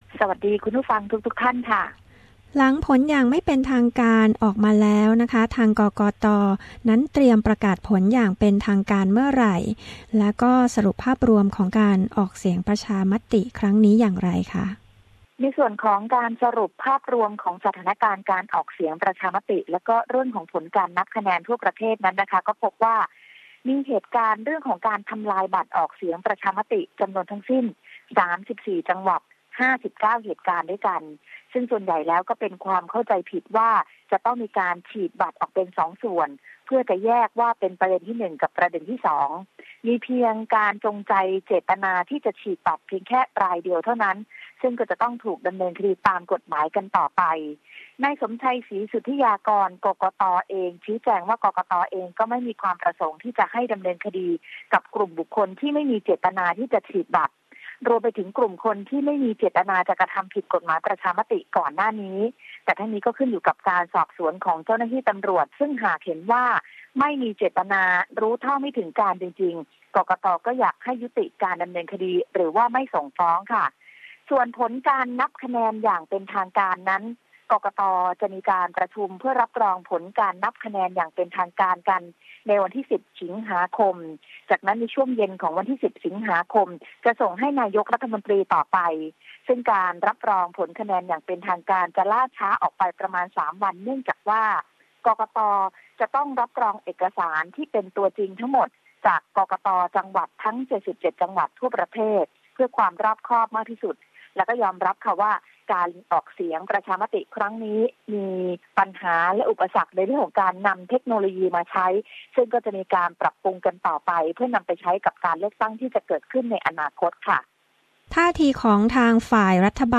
เอสบีเอส ไทย เกาะติดผลการลงประชามติ ซึ่งประชาชนส่วนใหญ่รับร่างรัฐธรรมนูญ ฝ่ายต่างๆ แสดงความเห็นอย่างไรหลังผลประชามติออกมาแล้ว และทิศทางการเมืองไทยจะเป็นอย่างไรต่อไป ติดตามได้จากรายงานสายตรงจากเมืองไทย ประจำวันที่ 8 สิงหาคม 2559